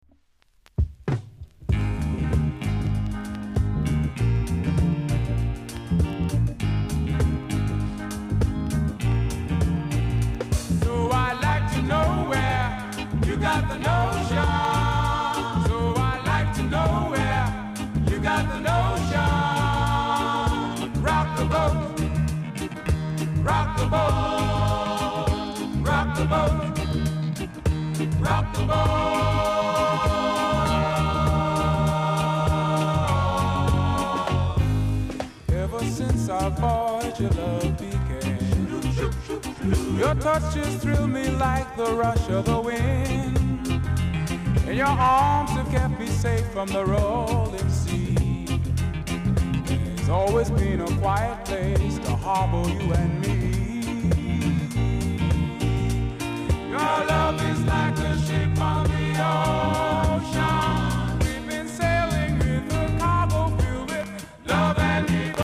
※小さなチリ、パチノイズが少しあります。
コメント BIG TUNE COVER!!JACOB MILLER　BOB MARLEY　THE WAILERS